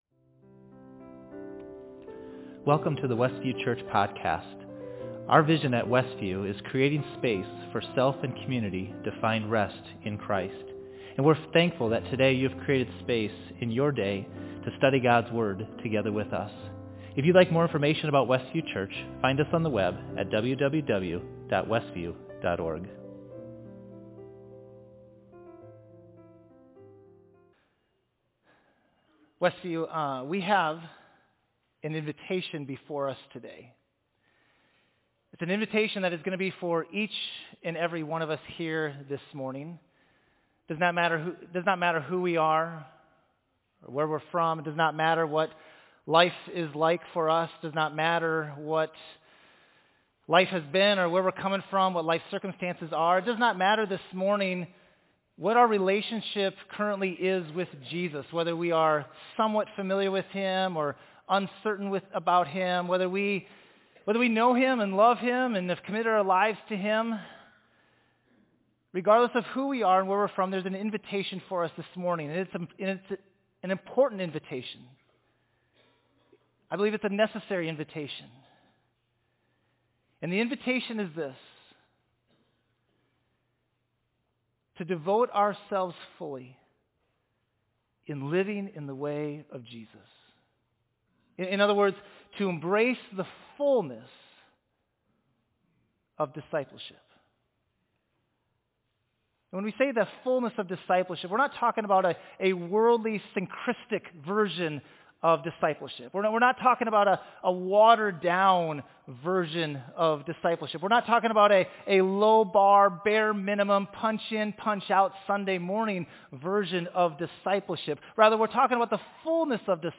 Thee Sermon